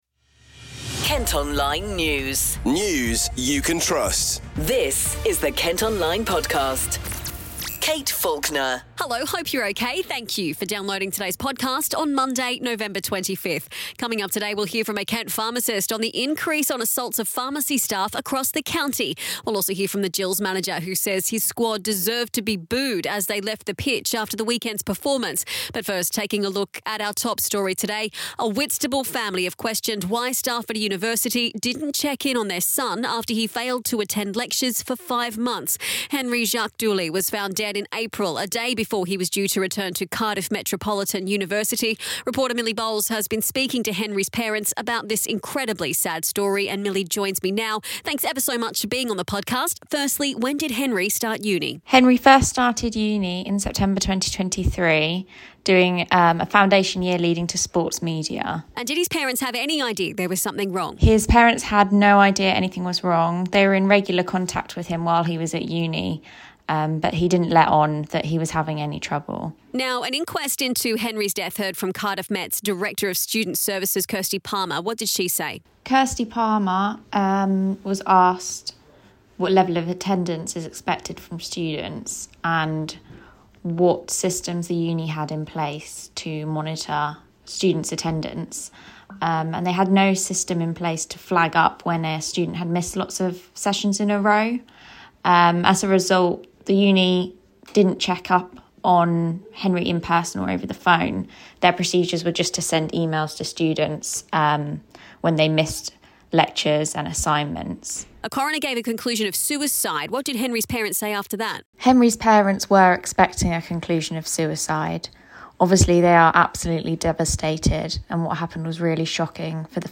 Also in today’s podcast, you can hear from a Kent pharmacist after figures showed an increase in assaults on pharmacy staff across the county.